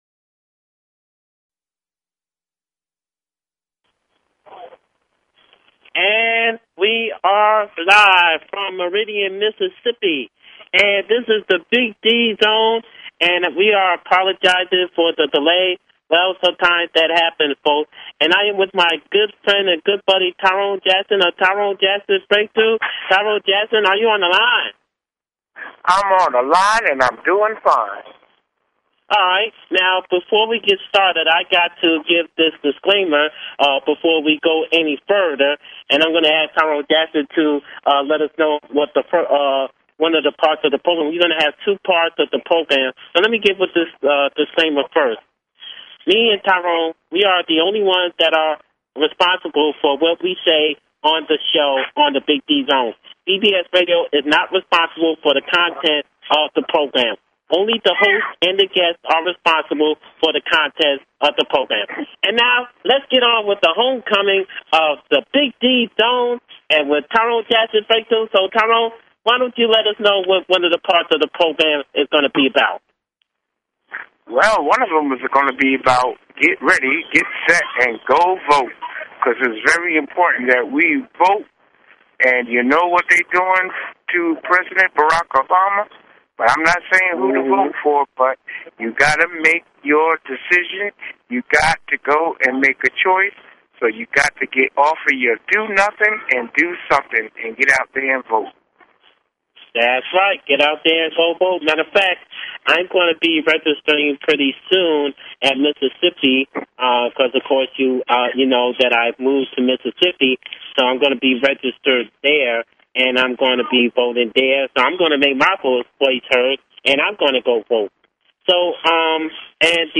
Talk Show Episode
The show will feature artists from R&B, RA, HIP HOPGOSPEL, POETRY, ROCK, AND MAY BE MORE!